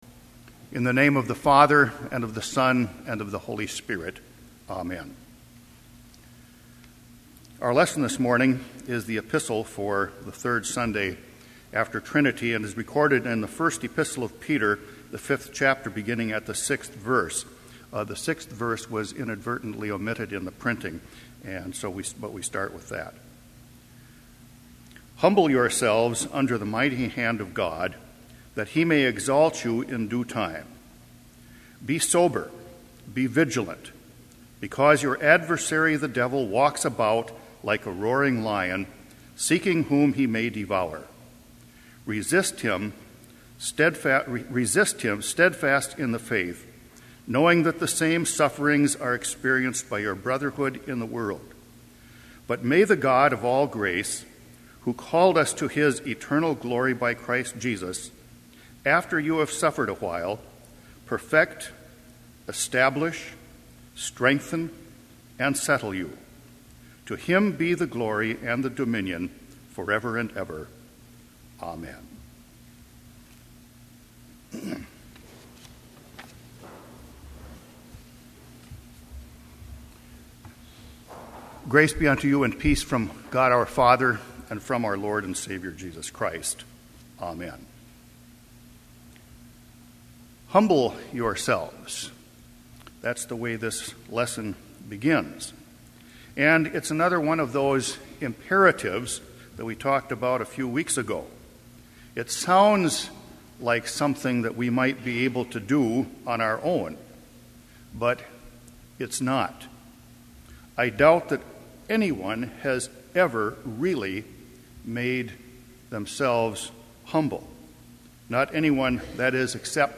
Bethany Lutheran College chapel service listing for June 2007, including names of the preacher and musicians participating in the worship services.